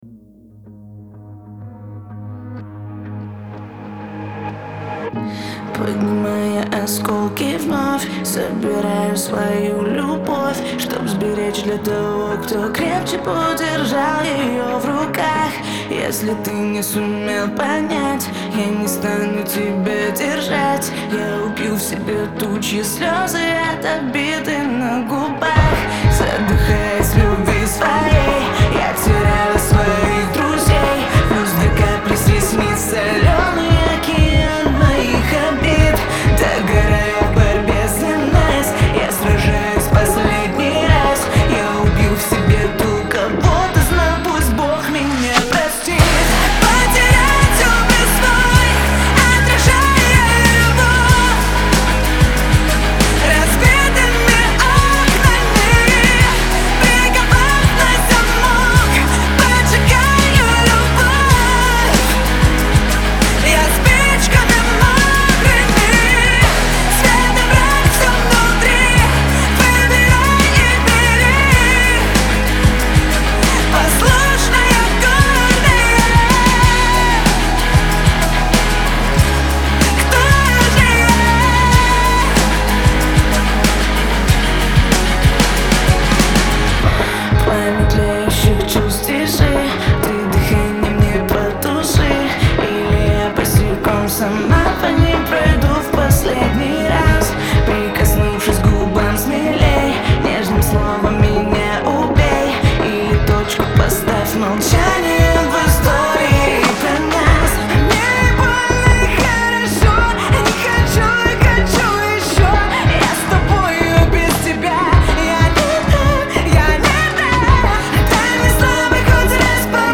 в жанре поп, наполненная меланхолией и искренними эмоциями.